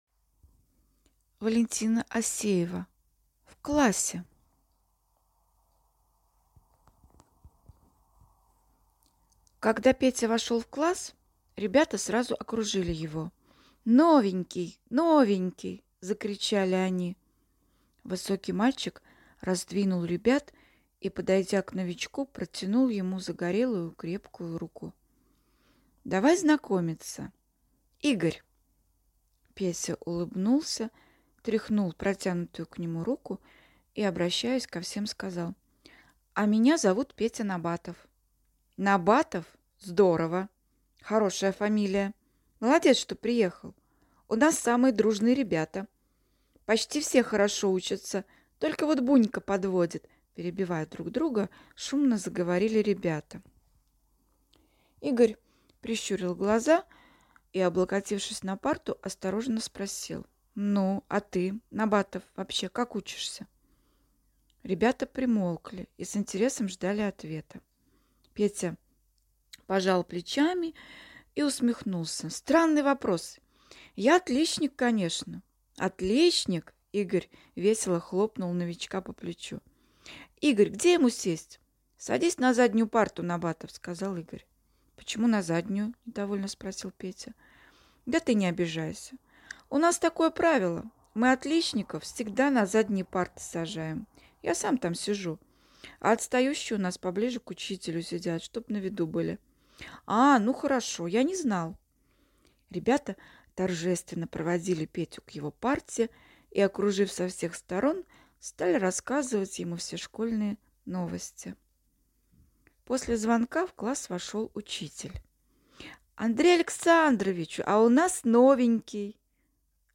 В классе - аудио рассказ Валентины Осеевой - слушать онлайн